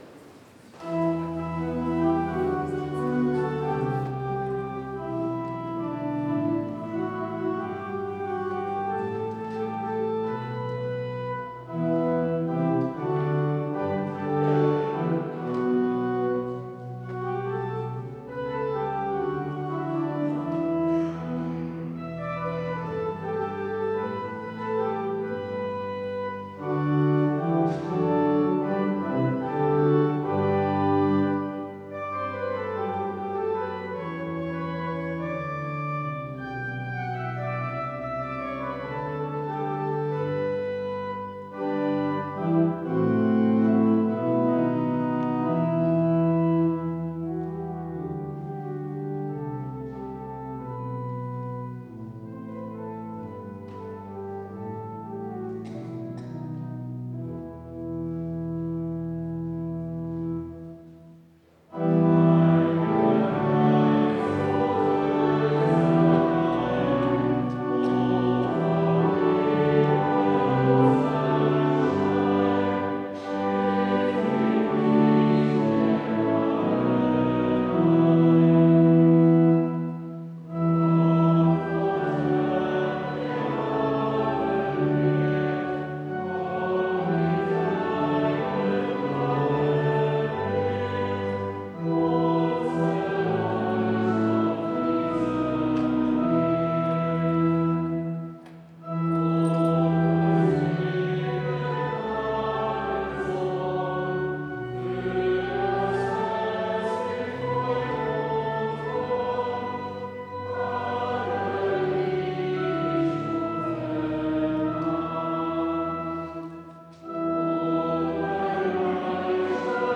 Audiomitschnitt unseres Gottesdienstes vom 3. Sonntag nach Trinitatis 2025.